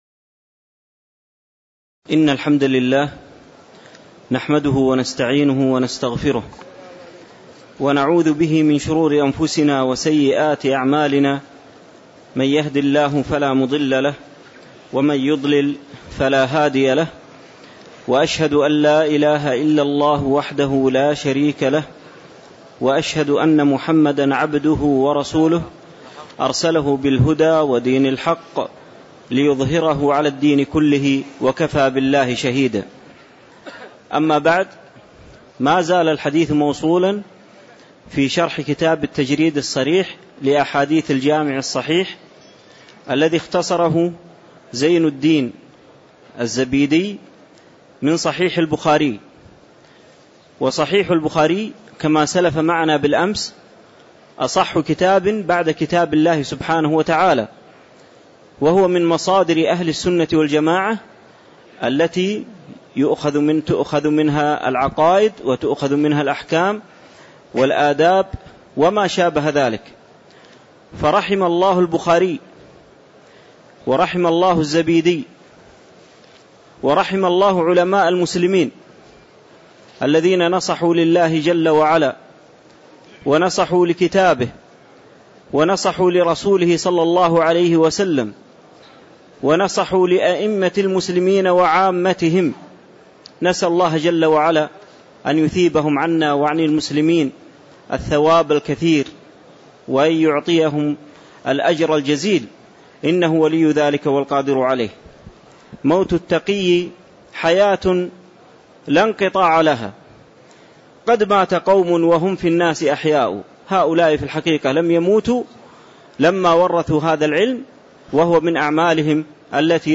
تاريخ النشر ١٤ ذو القعدة ١٤٣٧ هـ المكان: المسجد النبوي الشيخ